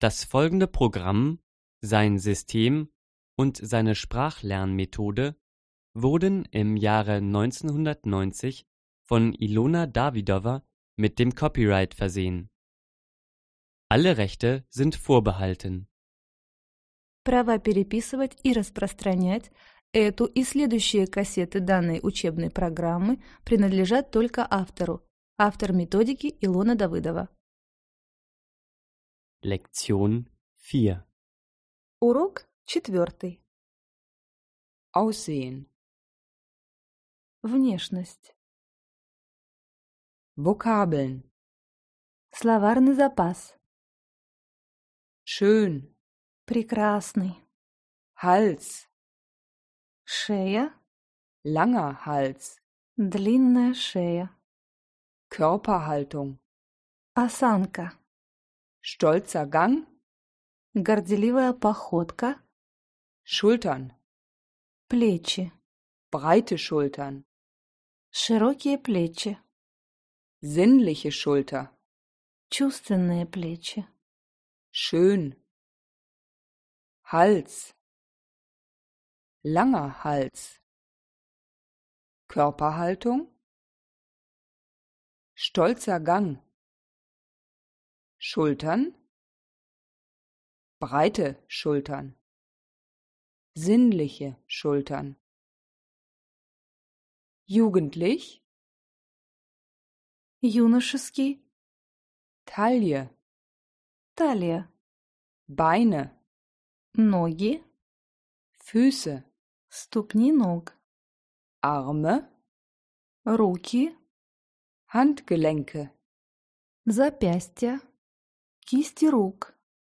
Аудиокнига Разговорно-бытовой немецкий язык. Диск 4 | Библиотека аудиокниг